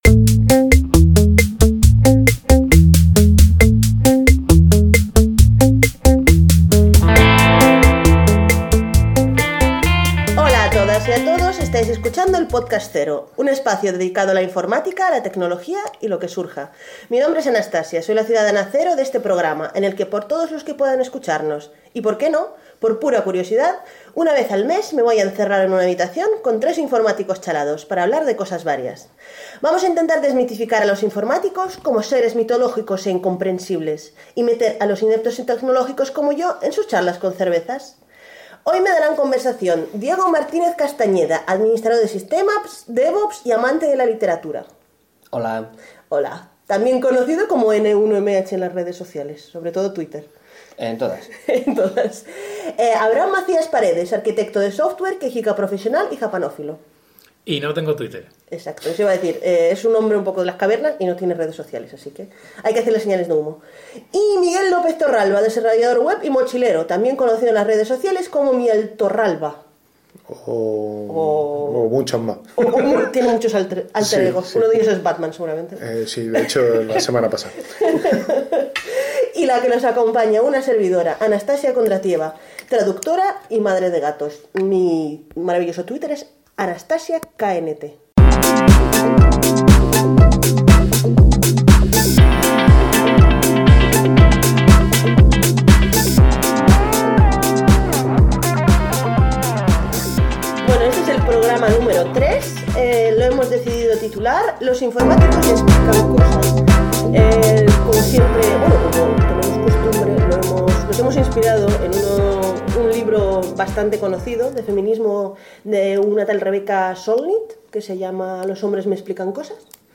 11:03 am Mala elección poner a hombres opinando sobre esto.